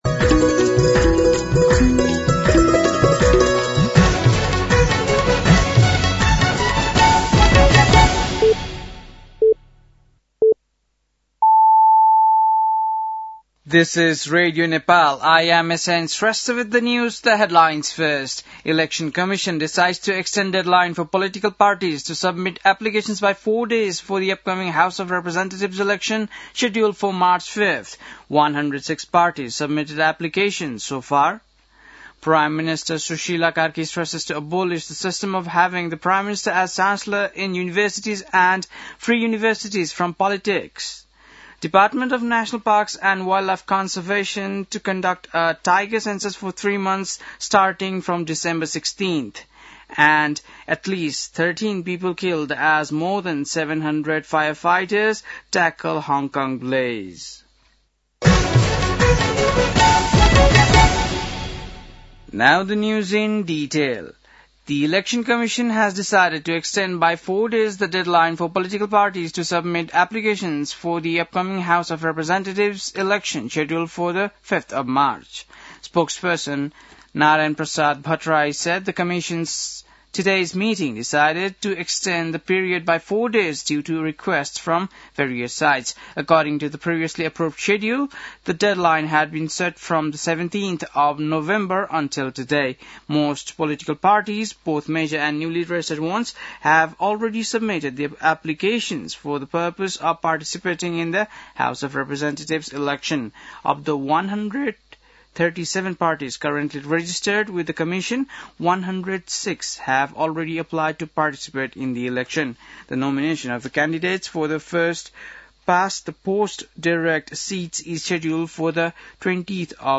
बेलुकी ८ बजेको अङ्ग्रेजी समाचार : १० मंसिर , २०८२
8-pm-NEWS-08-10.mp3